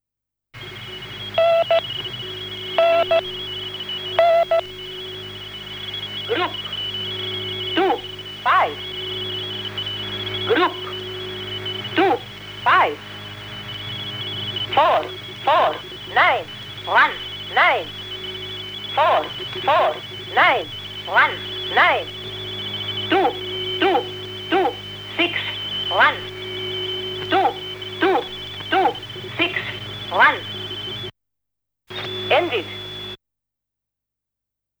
On each transmission, the station would play a morse code "N" for five minutes, read the group count twice, then read the 5-digit paired number groups. From there, the message would repeat.